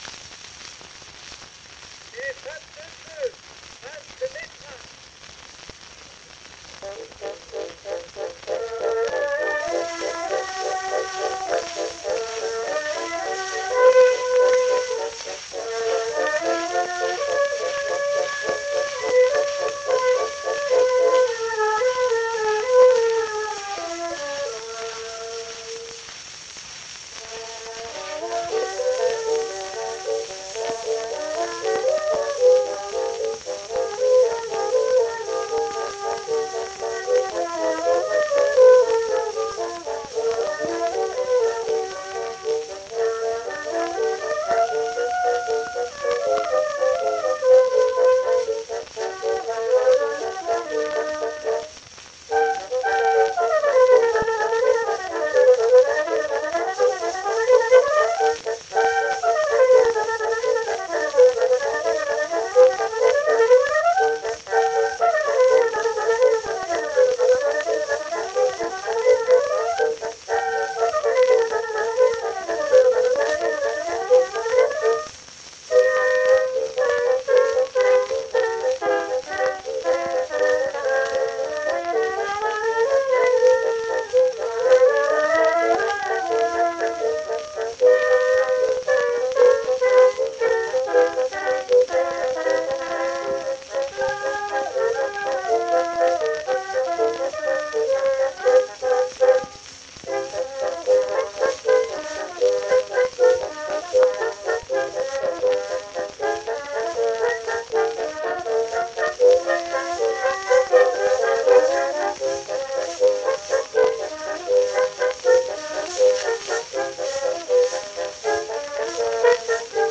Sie spielten bei der Aufnahme typischerweise in gleichmäßig hoher Lautstärke.
Ubekannte Bläsergruppe: Les femmes de feu, valse (Olivier Métra).